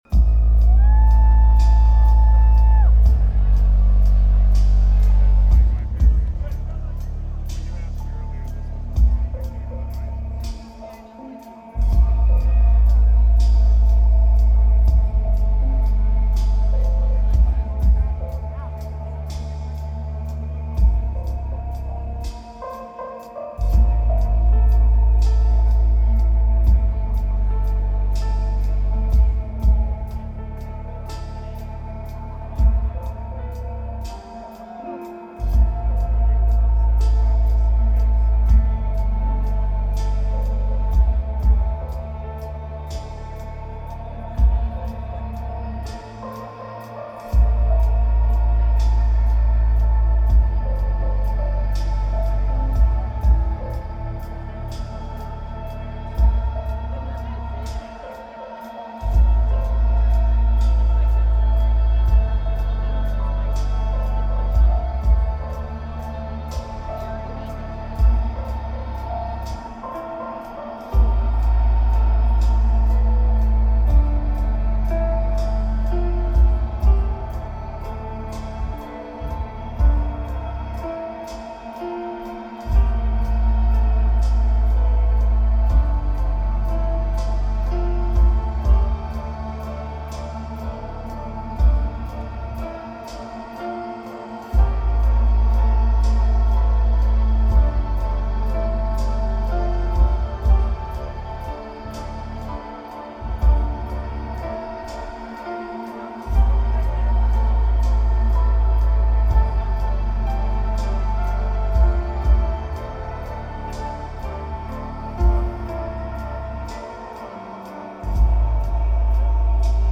Red Hat Amphitheater
Lineage: Audio - AUD (Sony PCM-A10)
I am impressed by the quality of the internal mics on these!